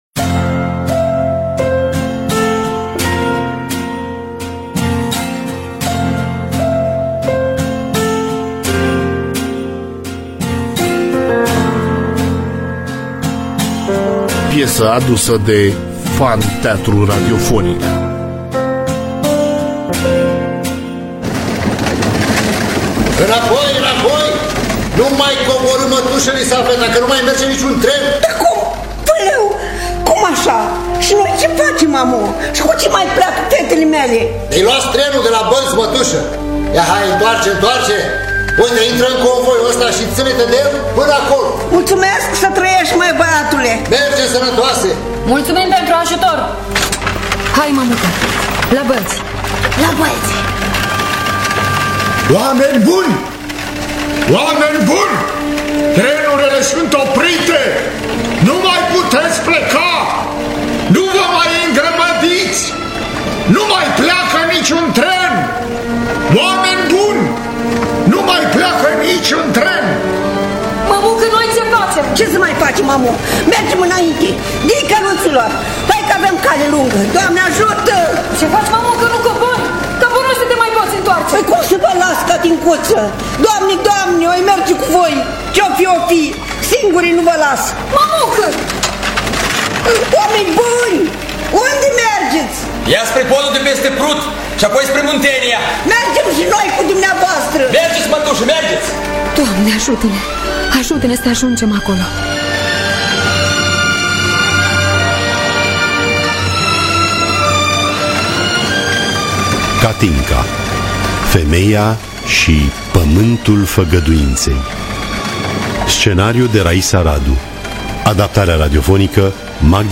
Femeia Si Pamantul Fagaduintei (2020) – Teatru Radiofonic Online